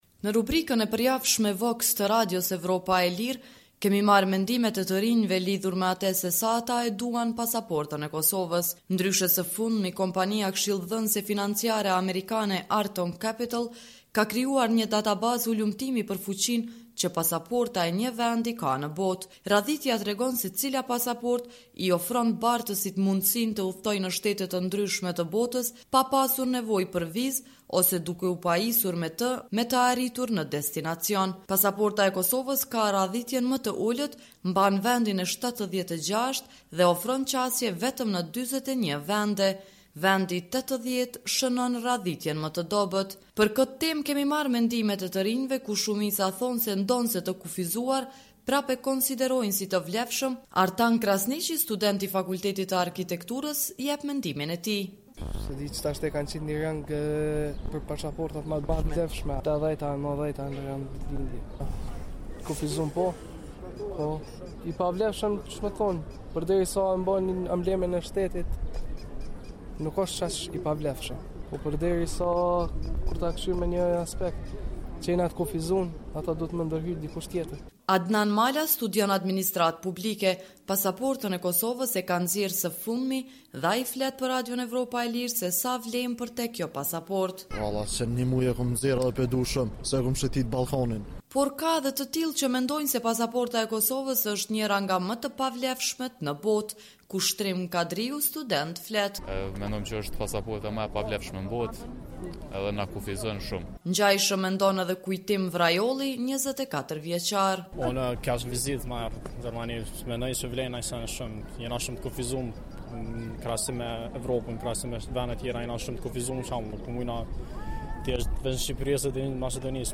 Ndër të rinjtë që takuam në rrugët e Prishtinës, kishte shumë nga ta që ndonëse mbi 18 vjeç, ende nuk kishin pasaporta.